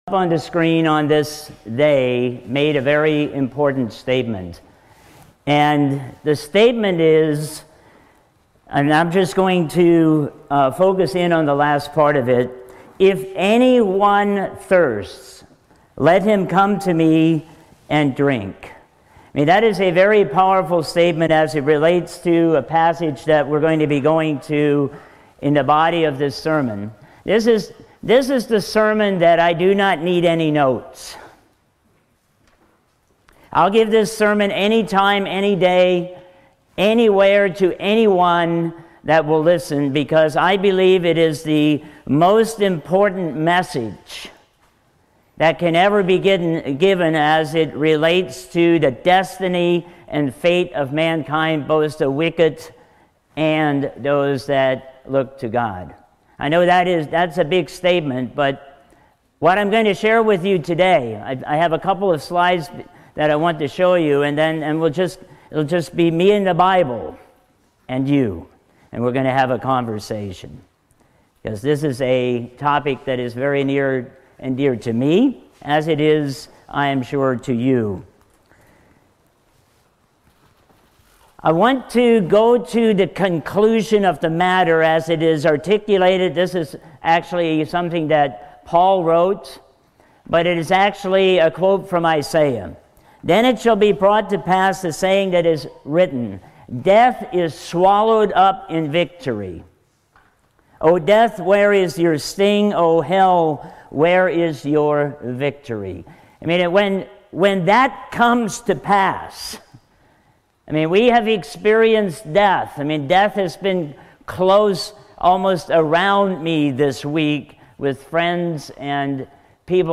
Sermons
Given in North Canton, OH Sugarcreek, OH